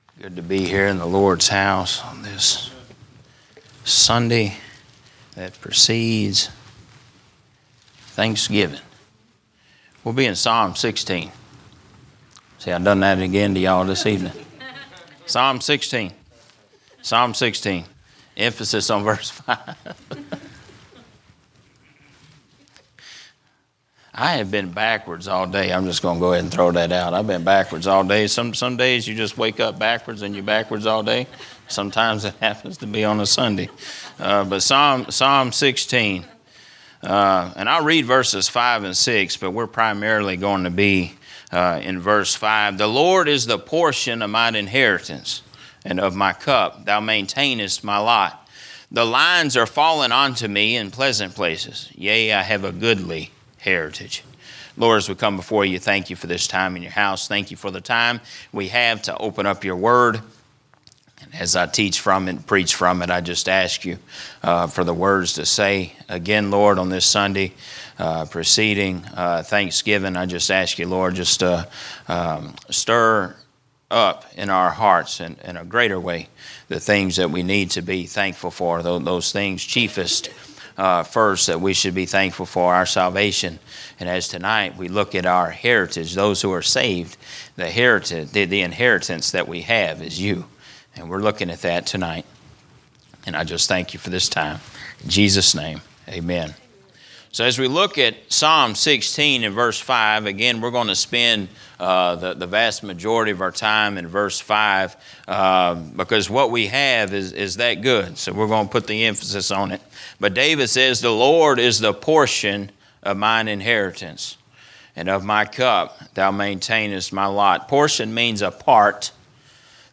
A message from the series "General Preaching." A look into the Lord's prayer